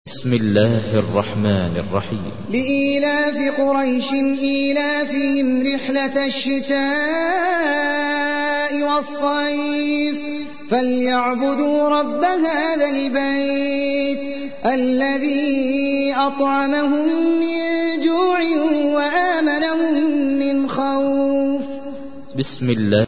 تحميل : 106. سورة قريش / القارئ احمد العجمي / القرآن الكريم / موقع يا حسين